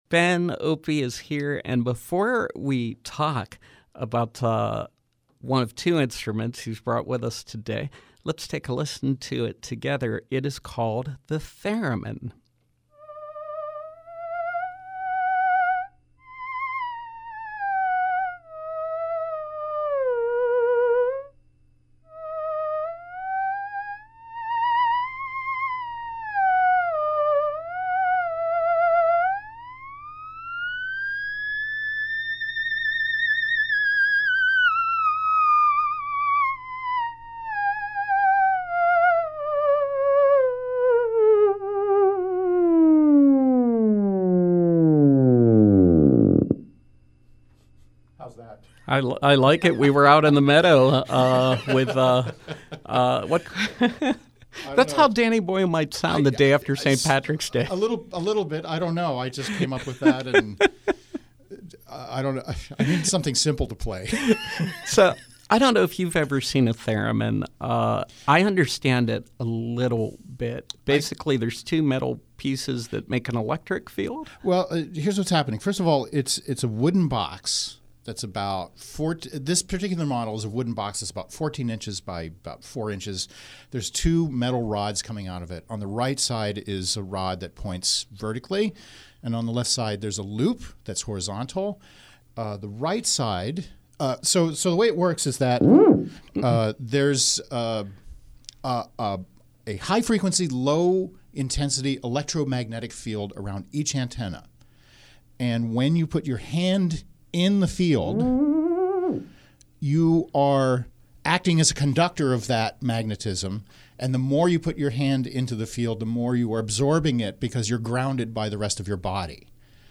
In Studio Pop-Up